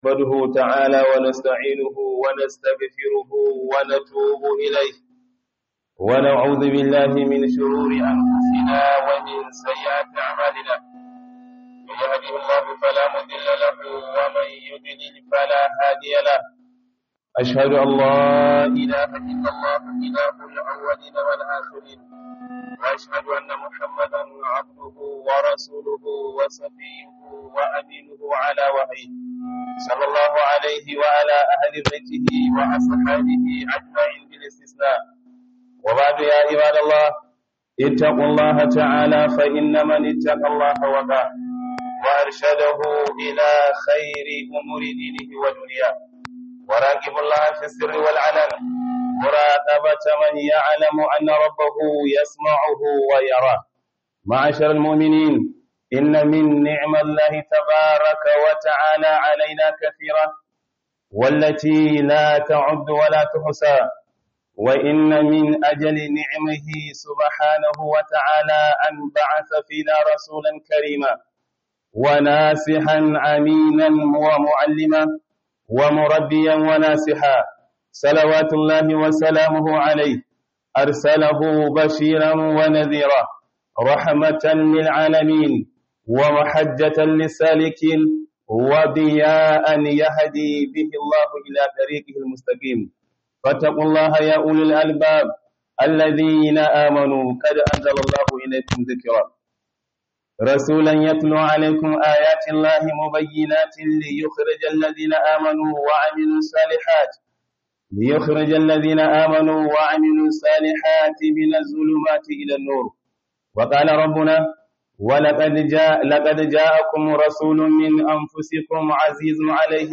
KHUDUBAR JUMA'A